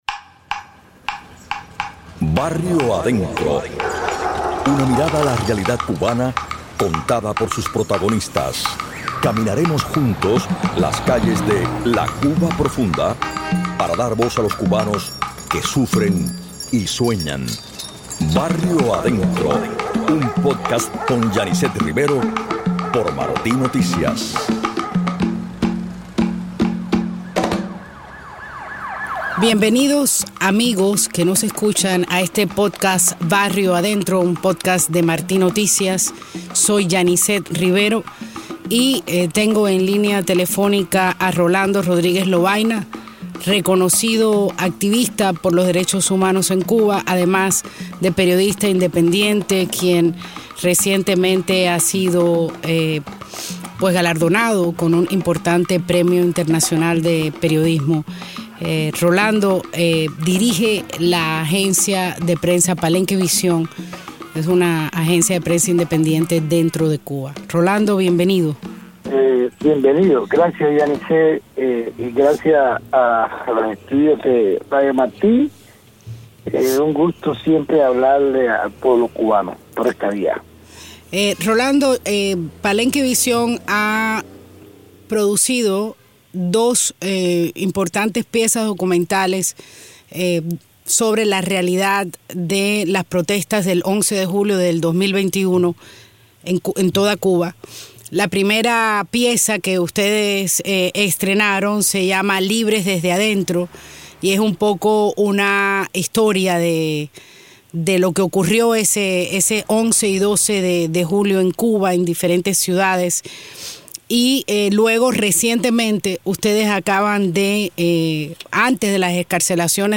Entrevista al periodista independiente y activista